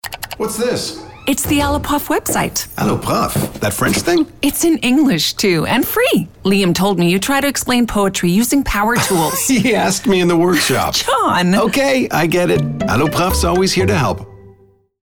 Commercial (Alloprof) - EN